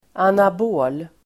Ladda ner uttalet
anabol adjektiv, anabolic Uttal: [anab'å:l] Böjningar: anabolt, anabola Förklaring: Anabola steroider är förbjudna i idrottssammanhang.